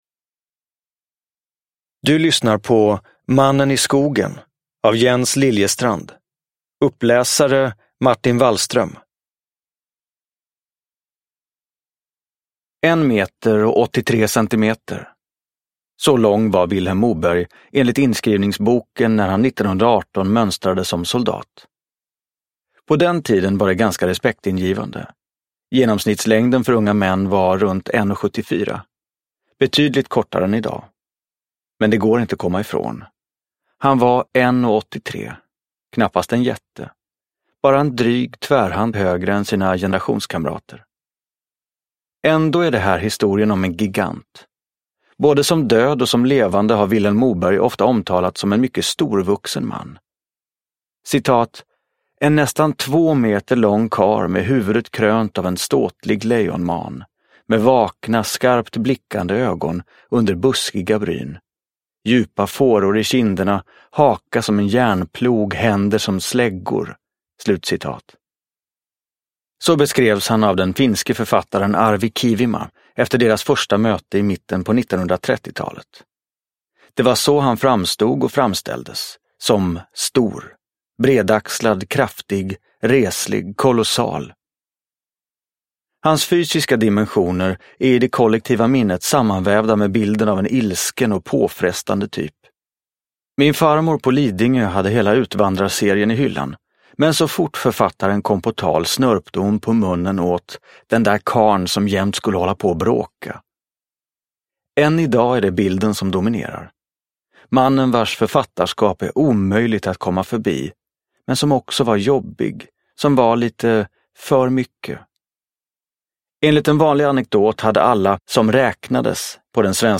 Uppläsare: Martin Wallström